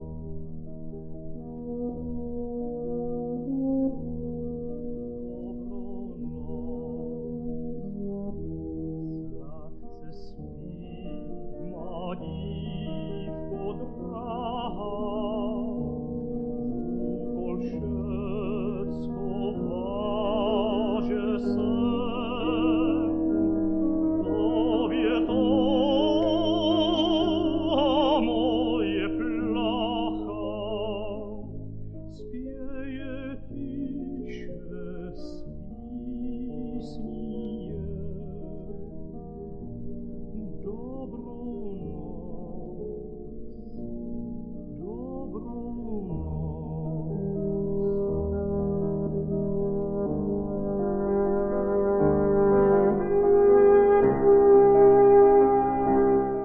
Beno Blachut vocal
piano
horns